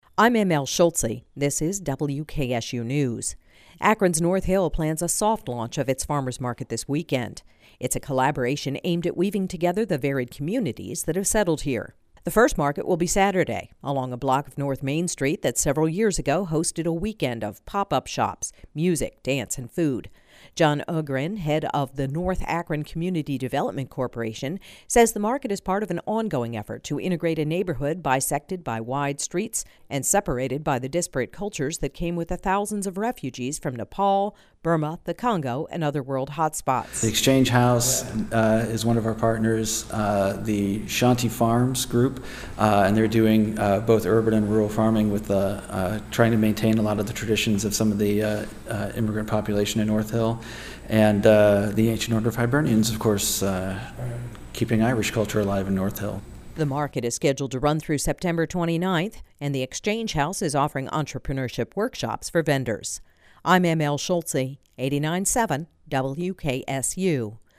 A farmers market and community crossroads